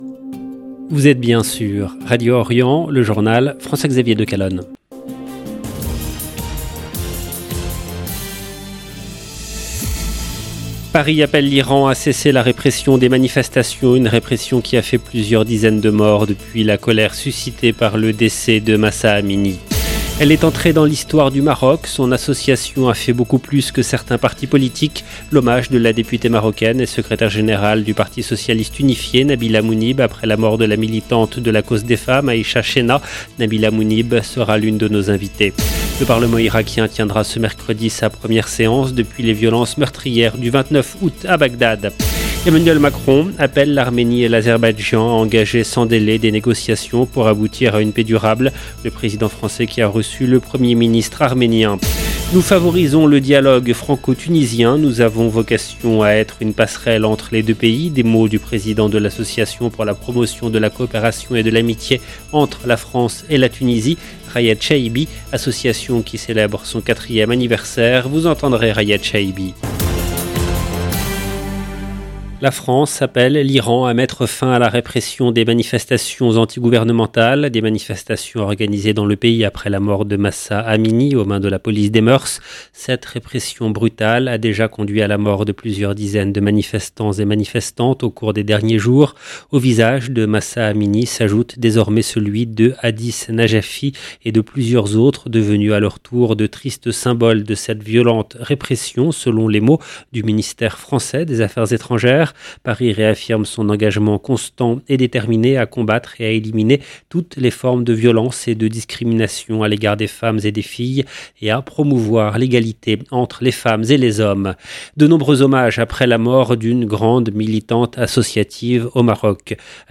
Nabila Mounib sera l’une de nos invitées. Le Parlement irakien tiendra ce mercredi sa première séance depuis les violences meurtrières du 29 août à Bagdad.